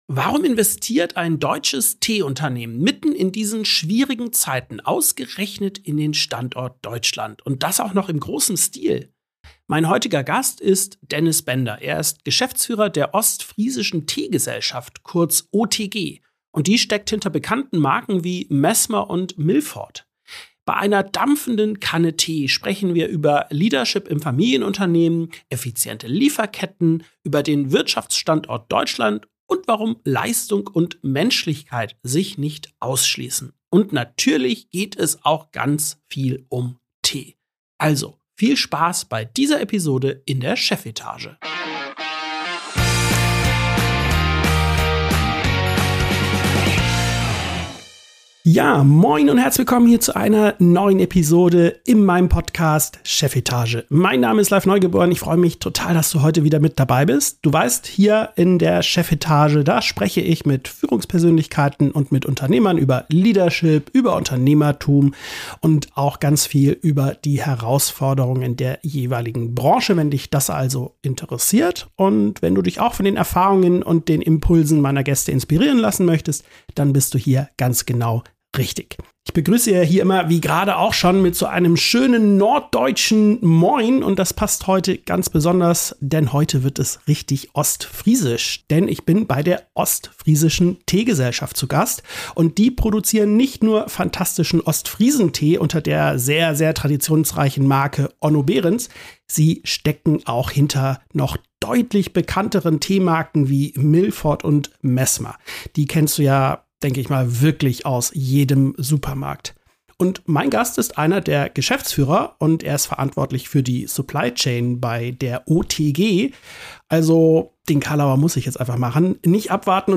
75 ~ Chefetage - CEOs, Unternehmer und Führungskräfte im Gespräch Podcast